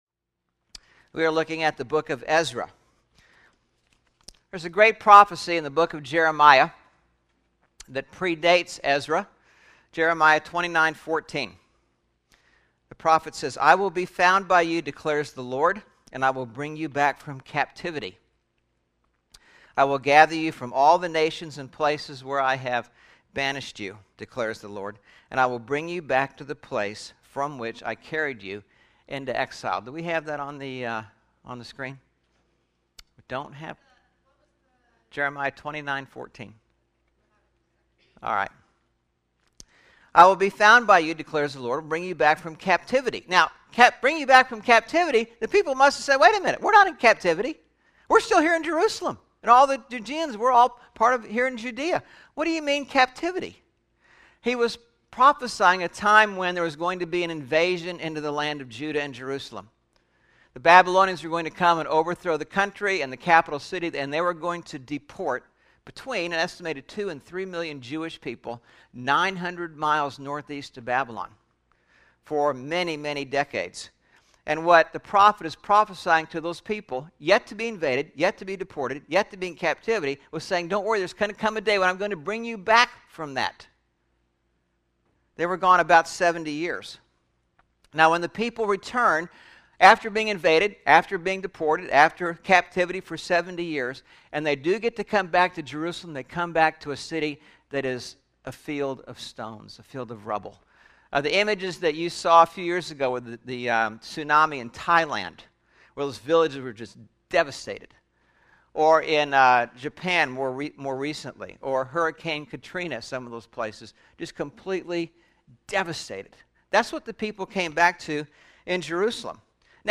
9/25/11 Sermon Re-building My Life (What Am I Supposed to Learn from Ezra?)